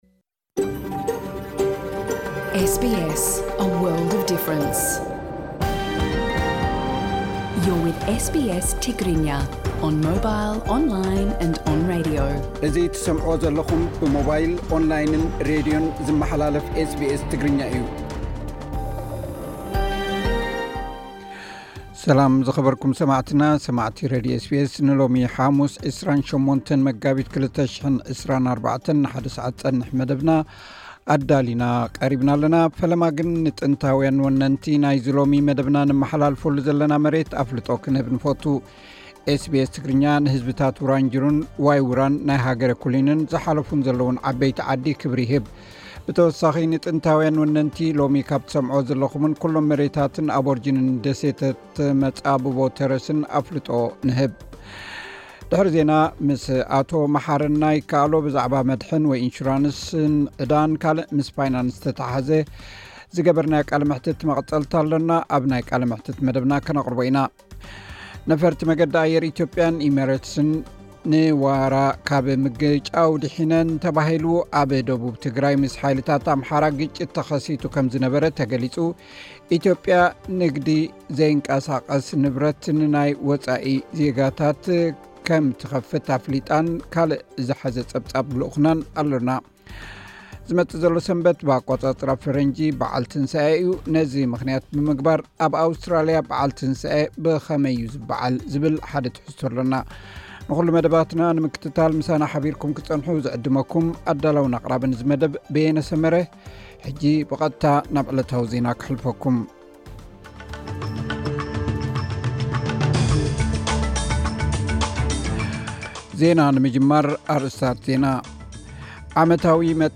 ብዛዕባ መድሕን ወይ ኢንሹራን፡ ዕዳን ካልእ ምስ ፋይናንስ ዝተሓሓዝ ዝገበርናዮ ቃለ መሕትት መቐጸልታ ኣለና፡ ኣብ ናይ ቃለ መሕትት መደብና ከነቕርቦ ኢና። ነፈርቲ መንገዲ ኣየር ኢትዮጵያን ኢሜረትስን ንሕምጢጥ ካብ ምግጫው ድሒነን ተባሂሉ። ኣብ ደቡብ ትግራይ ምስ ሓይልታት ኣምሓራ ግጭት ተኸሲቱ ከም ዝነበረ ተገሊጹ።